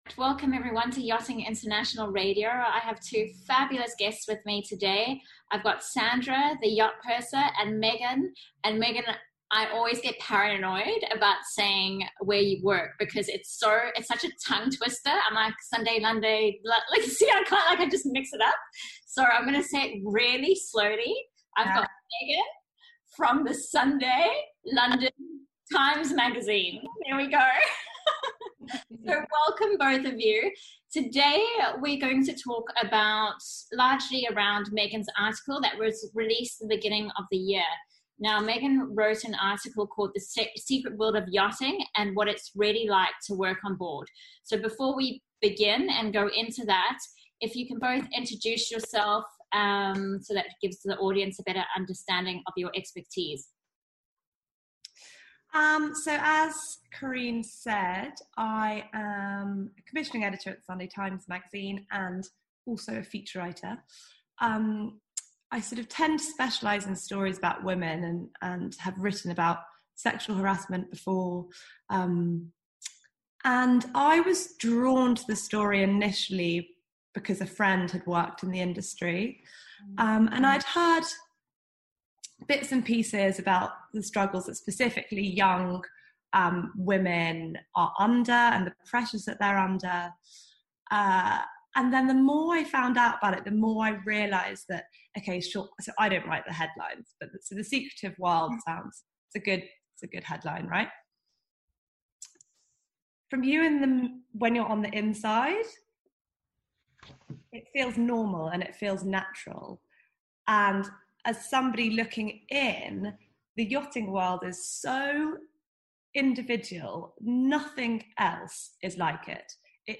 A very honest discussion with The Crew Coach, Sunday London Times and the Yacht Purser regarding sexual harassment and assault within our industry.